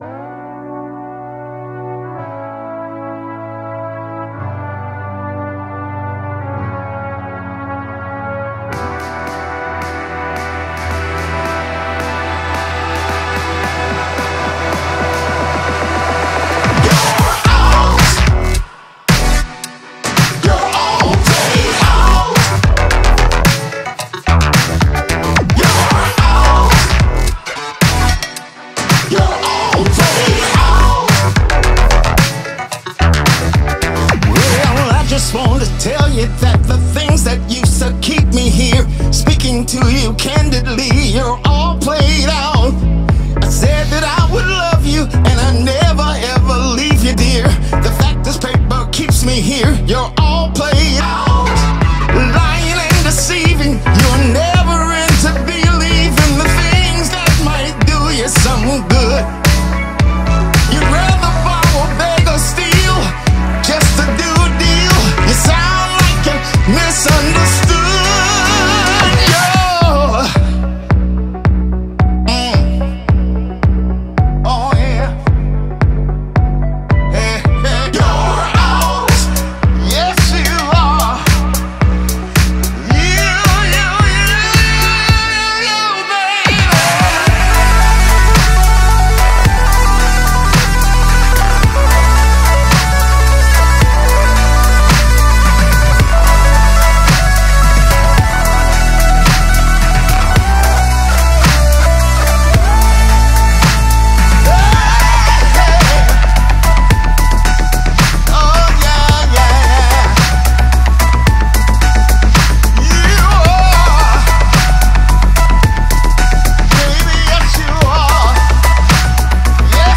BPM110
Comments[ELECTRONIC FUNK]